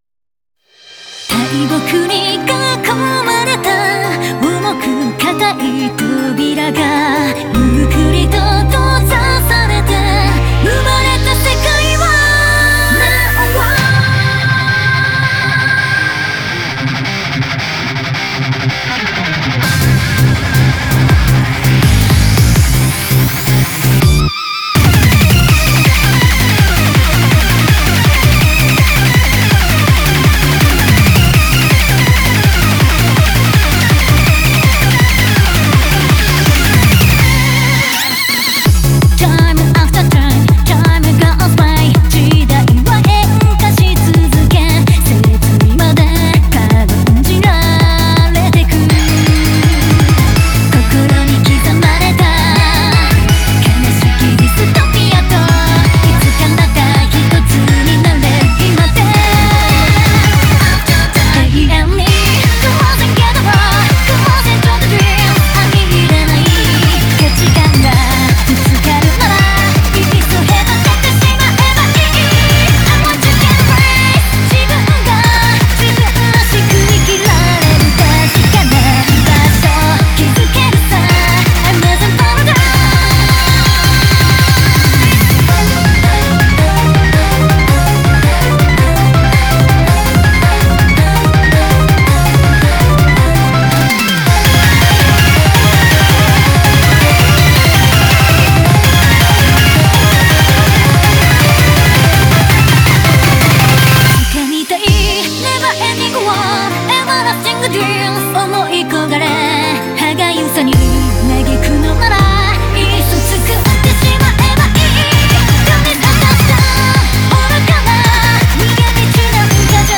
BPM154-164
Audio QualityPerfect (Low Quality)